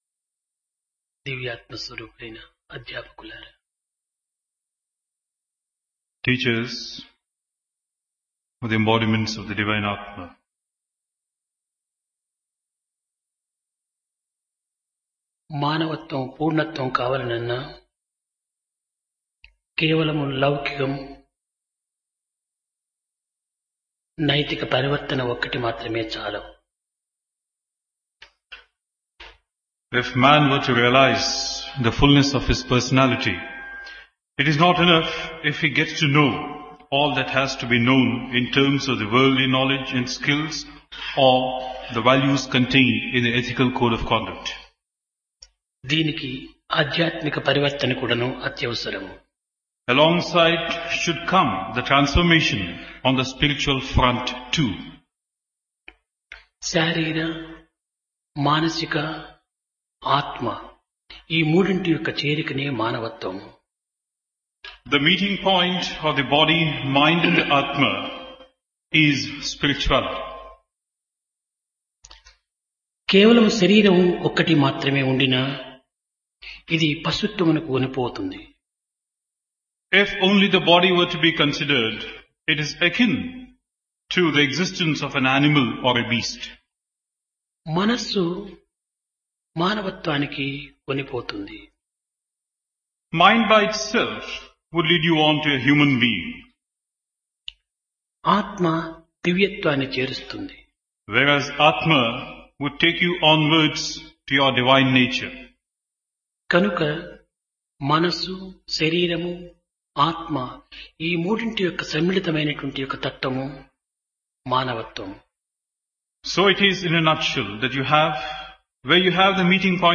Place Prasanthi Nilayam